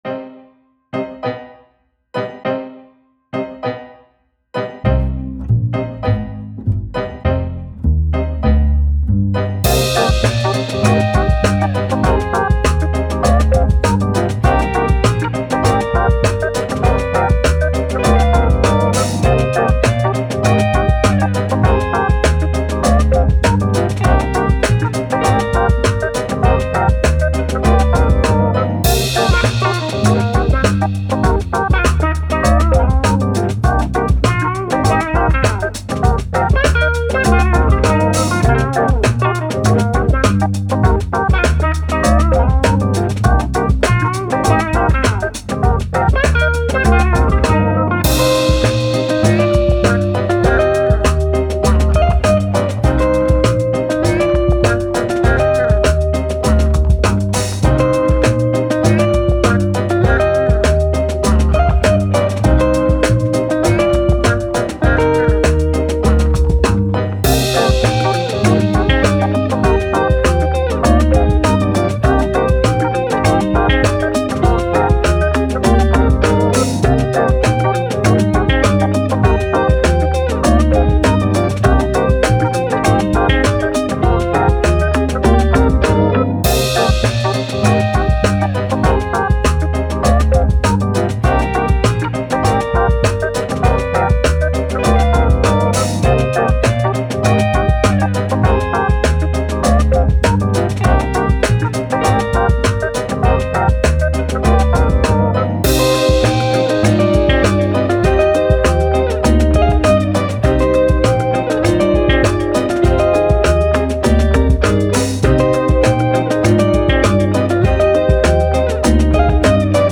Jazz, Hip Hop, Playful, Funky